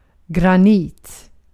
Ääntäminen
Ääntäminen Haettu sana löytyi näillä lähdekielillä: ruotsi Käännös Ääninäyte Substantiivit 1. granite US Artikkeli: en .